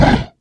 sound2 / sound / monster2 / fire_man / damage_1.wav
damage_1.wav